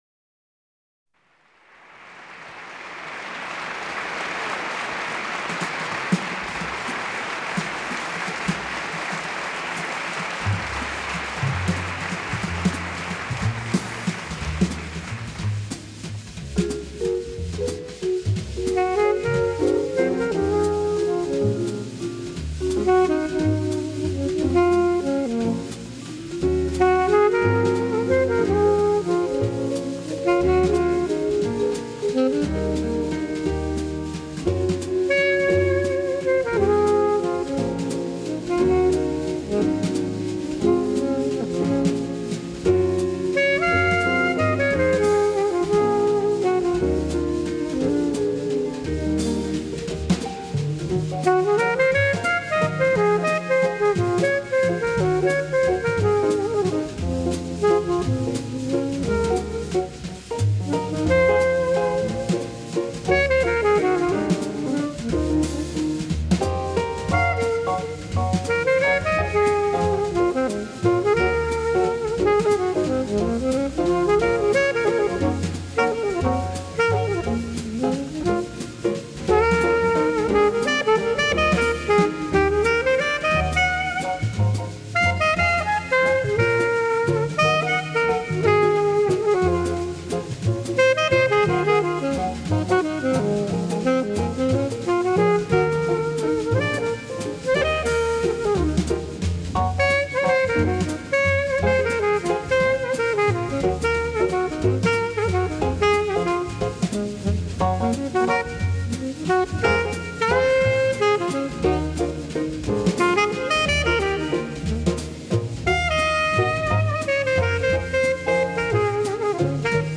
Saxophonist